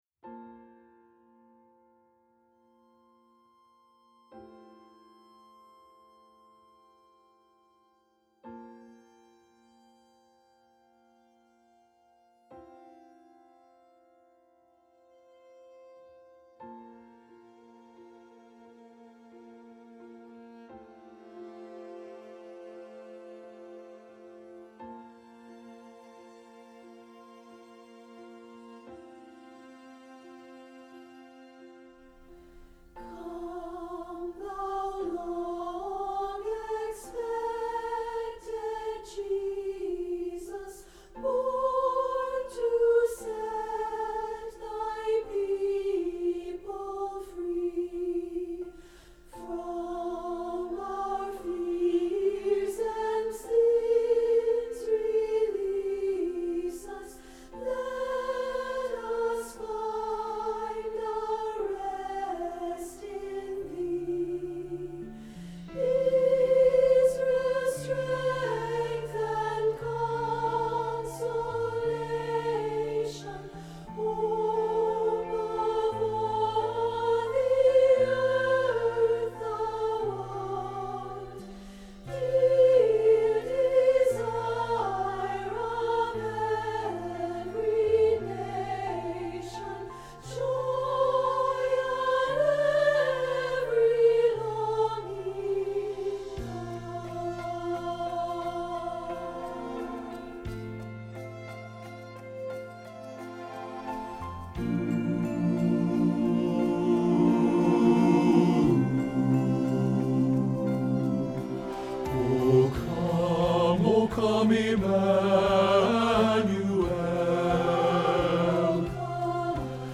O Come - Bass 2015-10-25 Choir